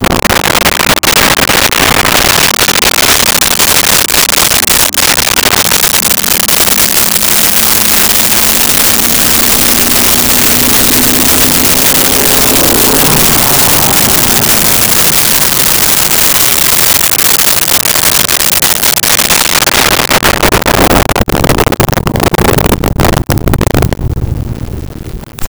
Muscle Car Fast By 100MPH
Muscle Car Fast By 100MPH.wav